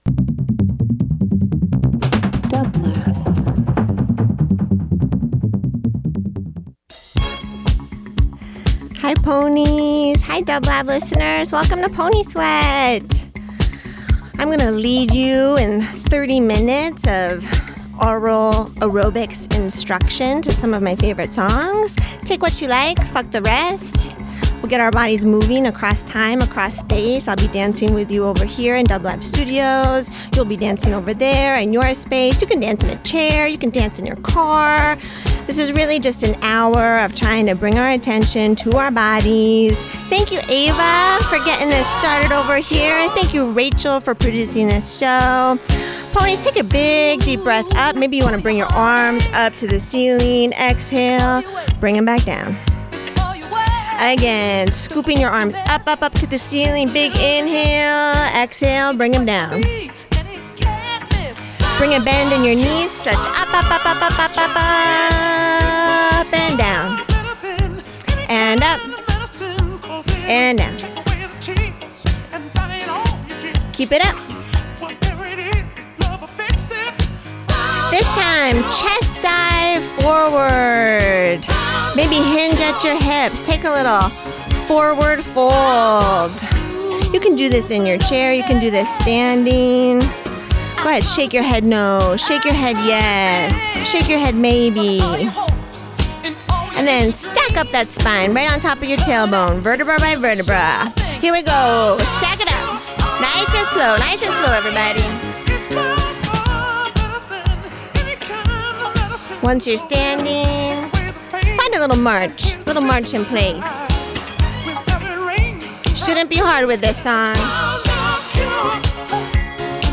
In this 30 min of aural aerobics set to some favorite tunes
Dance New Wave Pop Rock